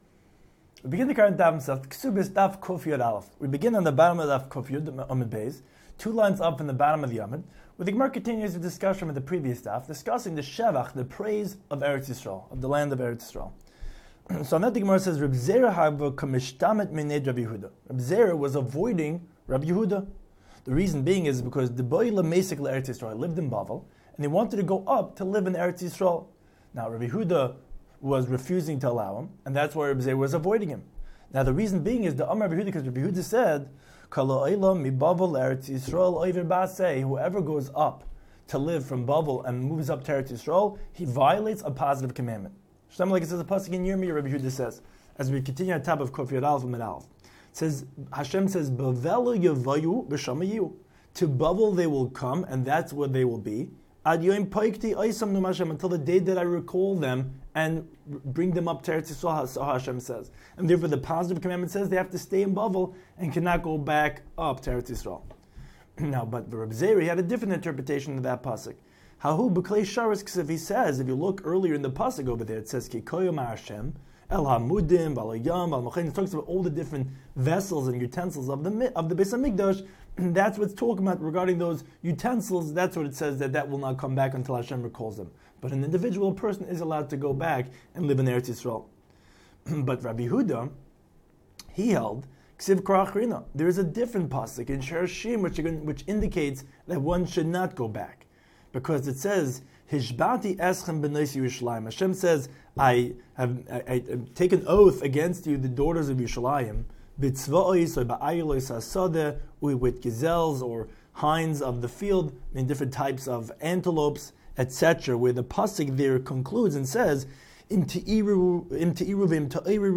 Daf Hachaim Shiur for Kesuvos 111